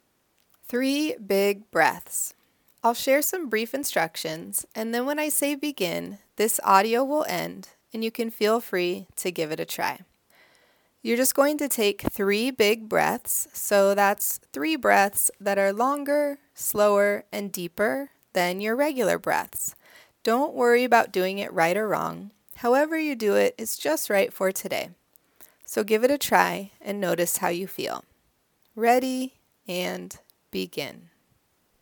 In this mini meditation you'll be guided to take three breaths that are longer, slower and deeper than your regular breaths.